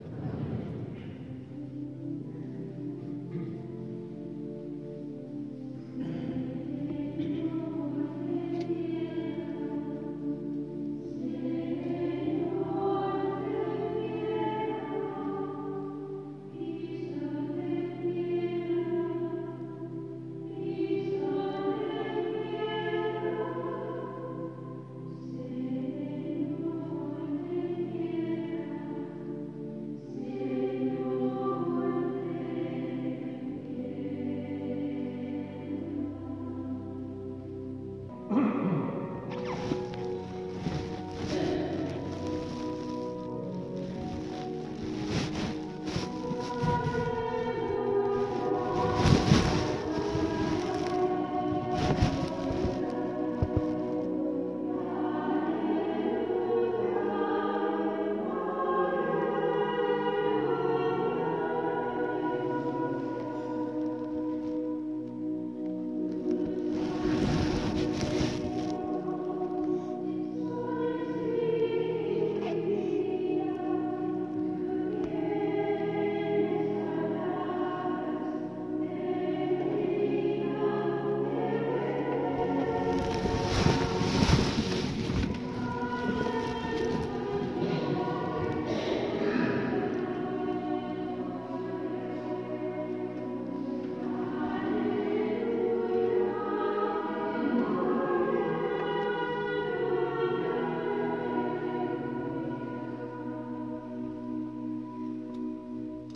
C’est au monastère de l’Incarnation, dans la chapelle de la Transverbération, que je me trouve à cette date.
J’ai rarement entendu Kyrie et Alléluia chantés avec autant d’amour. Je suis désolée du bruit durant l’Alléluia mais il vaut la peine:
Encarnación-messe-matin-26-août-pour-pmof.m4a